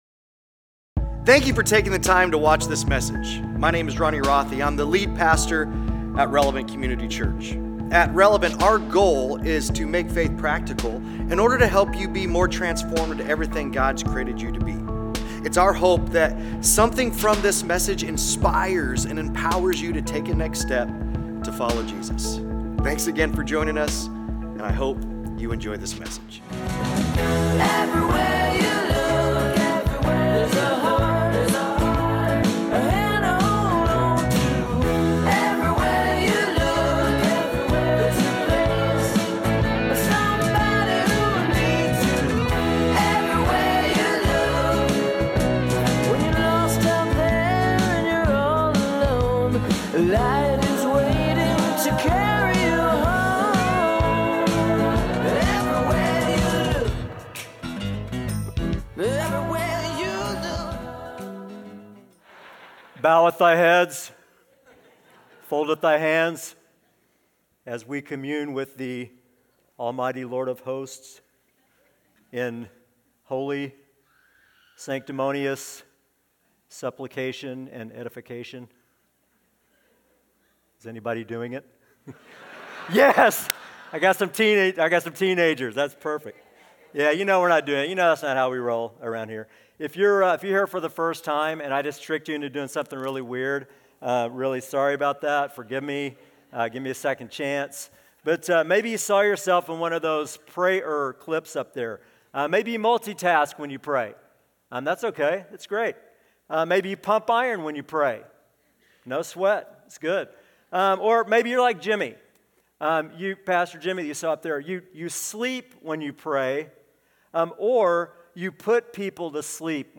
Sunday Sermons PRAY, Part 4: "Yield" Mar 17 2025 | 00:33:58 1x 00:00 / 00:33:58 Subscribe Share Apple Podcasts Spotify Overcast RSS Feed Share Link Embed